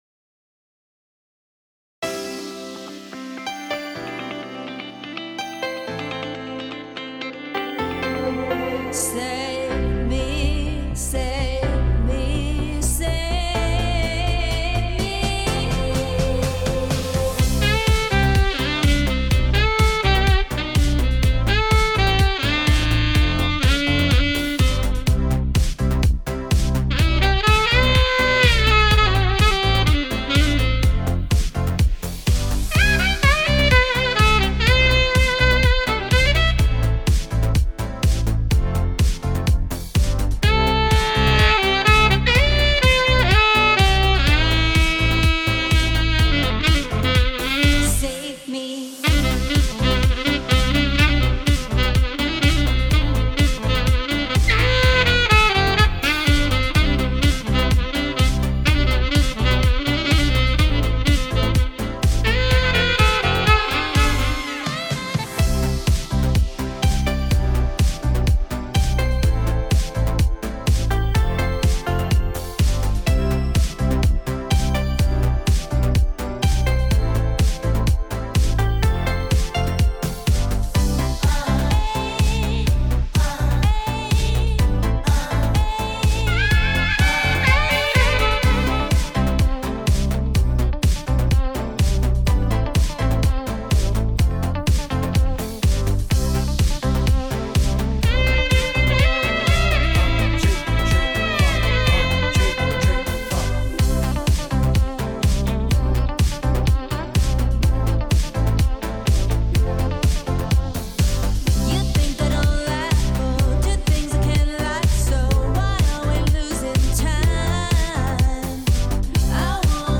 Ibiza sound